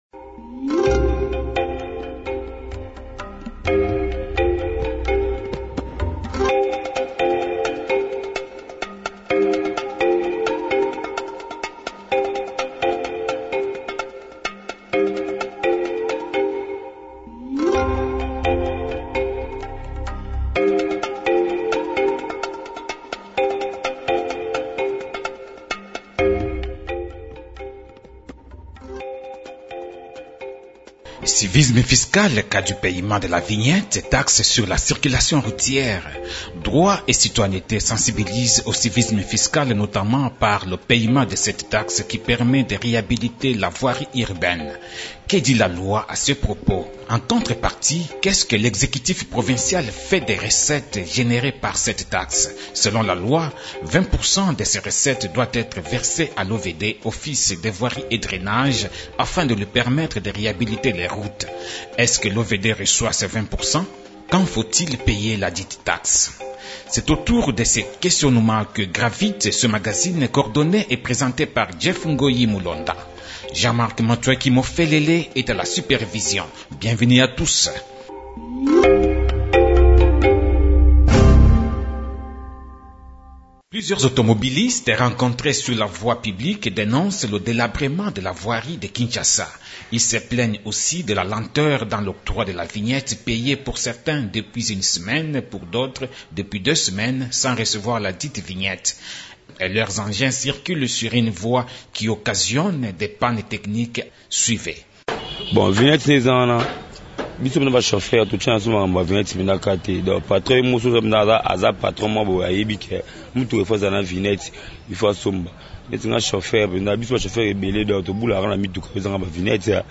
Le magazine de promotion des droits et devoirs se penche sur ce sujet.